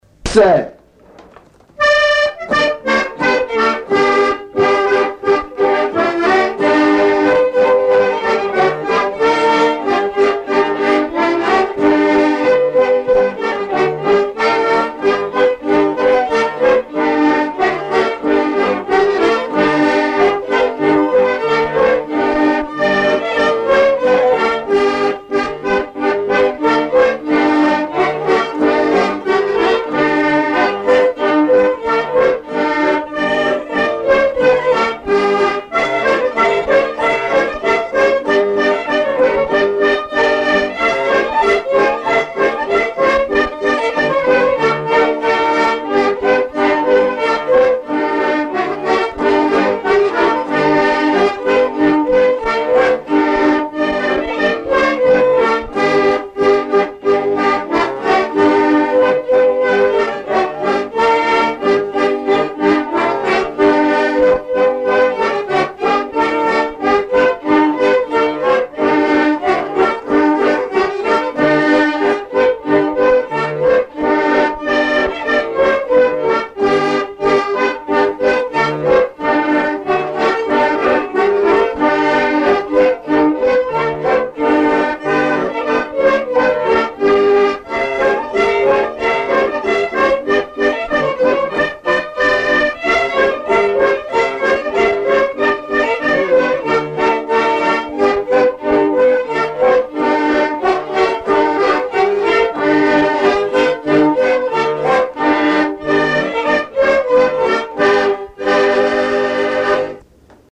danse : orsay
Pièce musicale inédite